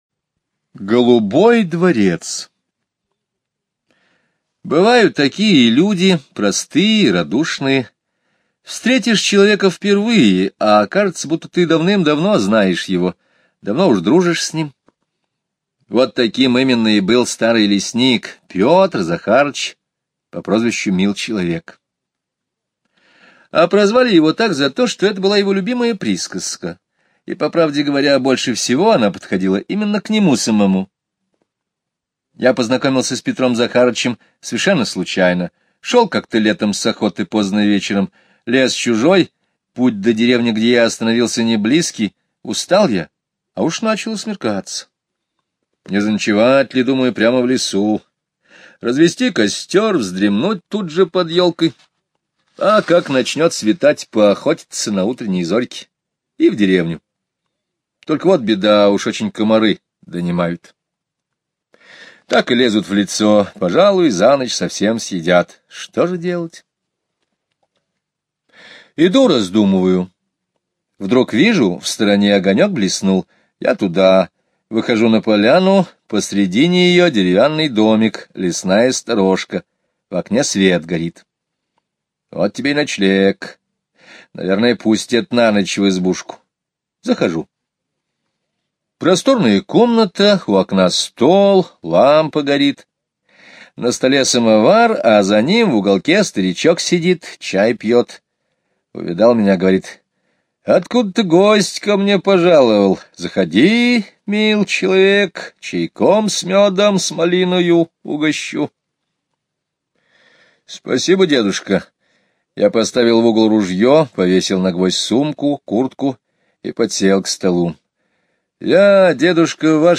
Слушайте аудио рассказ "Голубой дворец" Скребицкого Г. онлайн на сайте Мишкины книжки.